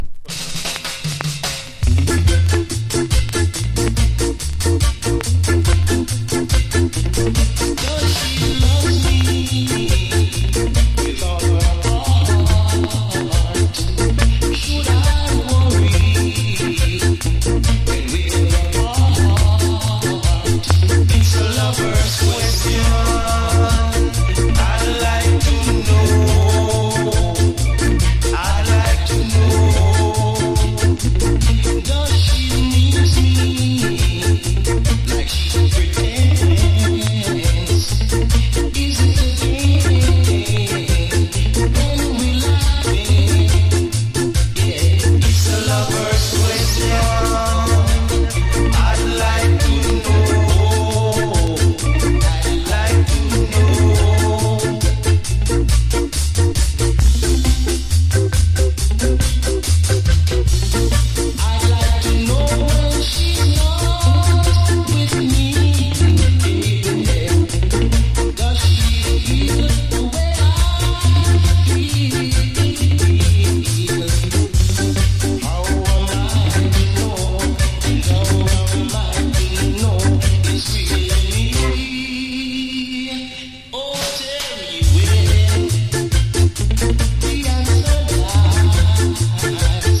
old形式 : 7inch / 型番 : / 原産国 : UK
所によりノイズありますが、リスニング用としては問題く、中古盤として標準的なコンディション。